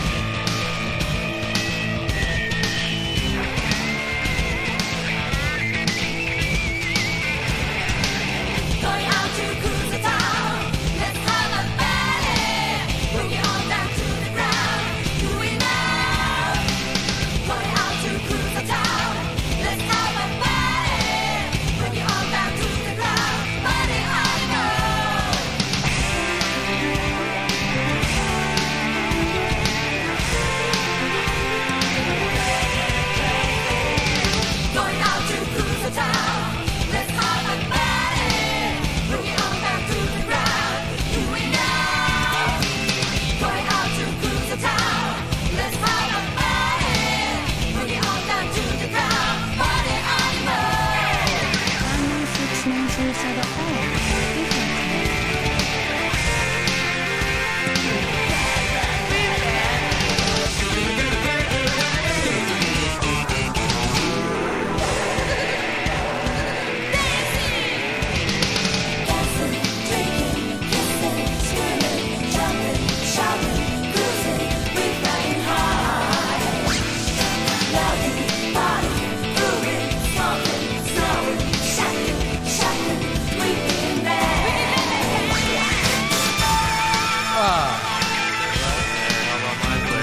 エンジン全開の冒頭曲から始まる、ハード・ドライヴィンでテクニカルなロックサウンドの元気印盤。ギターがとてもエッジ―です。
60-80’S ROCK